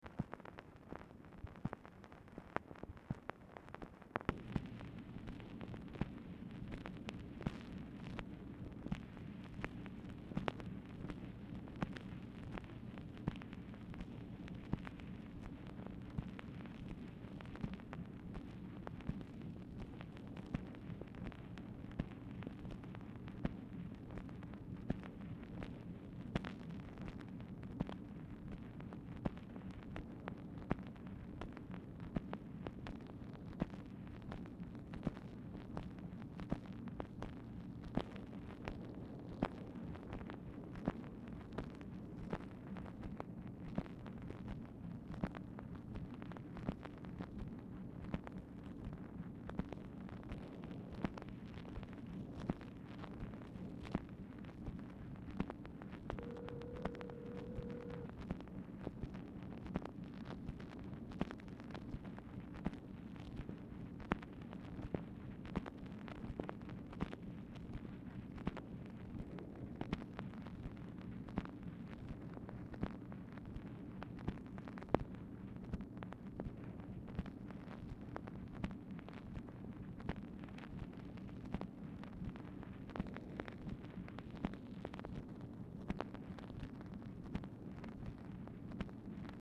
Telephone conversation # 11141, sound recording, MACHINE NOISE, 12/16/1966, time unknown | Discover LBJ
Format Dictation belt
Specific Item Type Telephone conversation